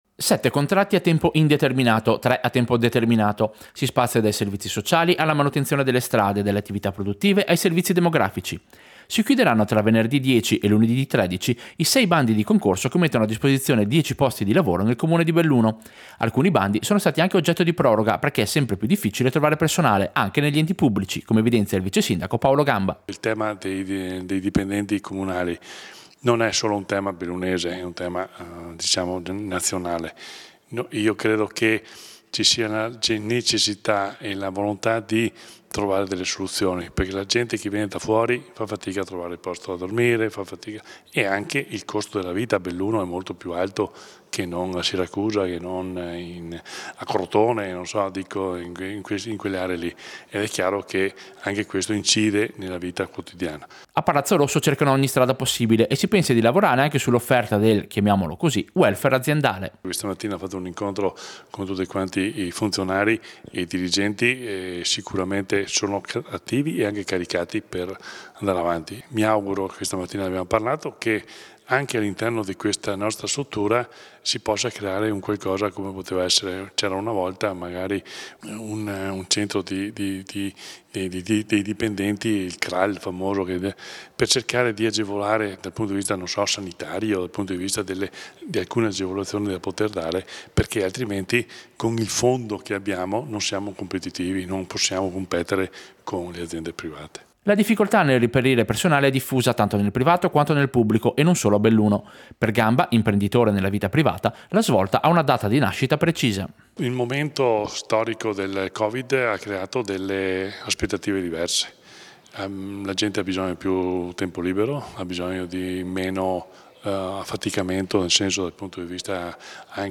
Servizio-Proroga-concorsi-Belluno.mp3